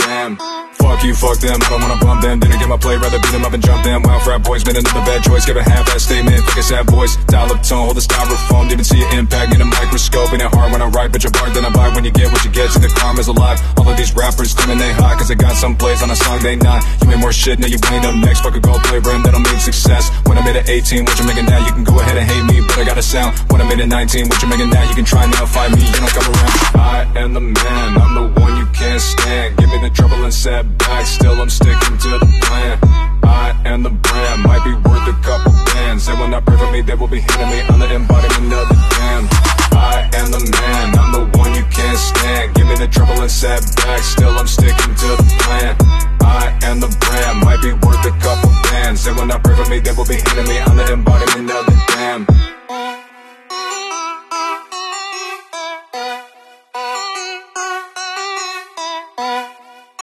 Ken Block Drift Sound Effects Free Download